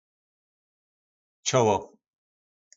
Slovník nářečí Po našimu